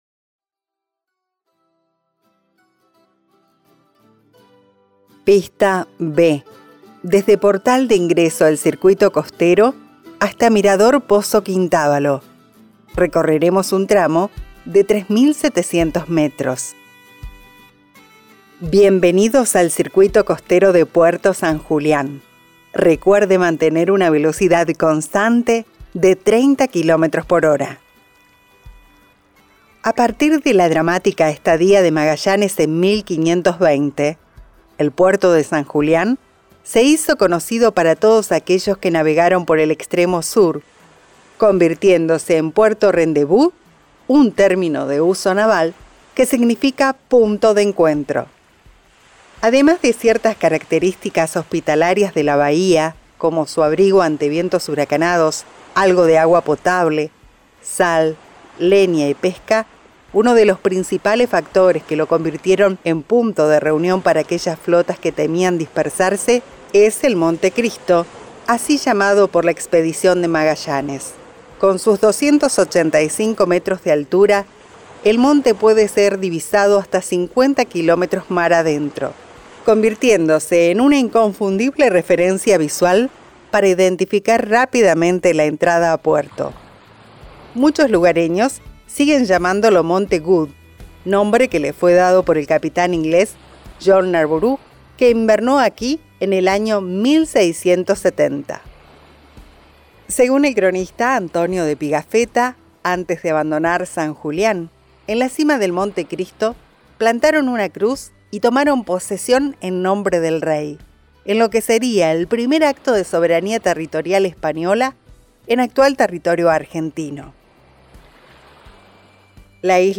Además, y para que esta propuesta sea más amena, incluimos también producciones musicales de nuestros artistas, que ilustran el paisaje y dan color al viaje.
Audioguía Vehicular Huelgas Patagónicas